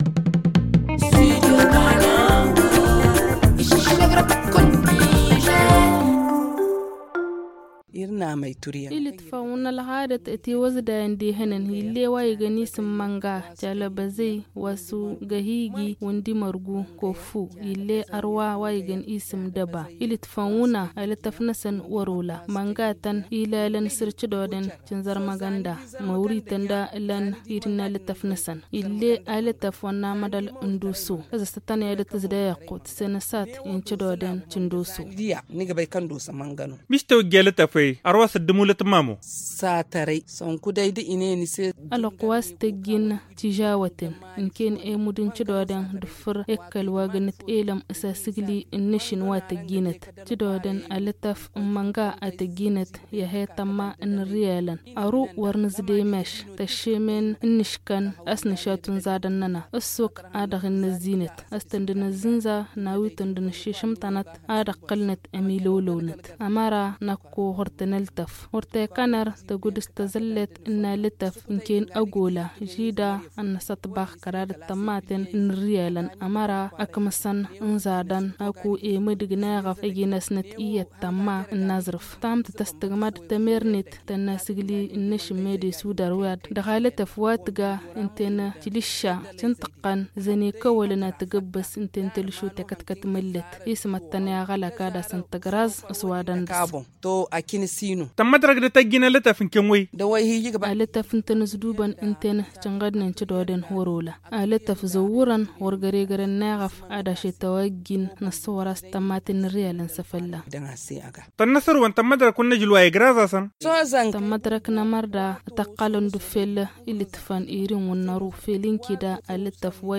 Le magazine en tamasheq